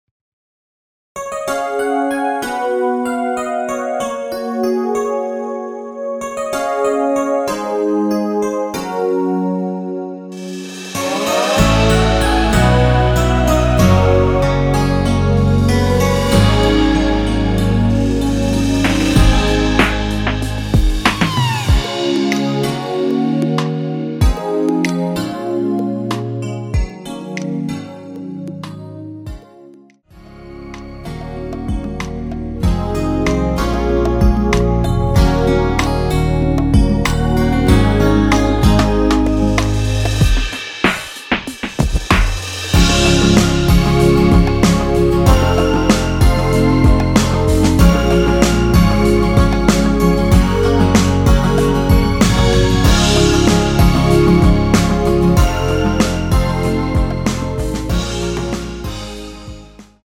원키에서(-3)내린 MR입니다.
Db
앞부분30초, 뒷부분30초씩 편집해서 올려 드리고 있습니다.
중간에 음이 끈어지고 다시 나오는 이유는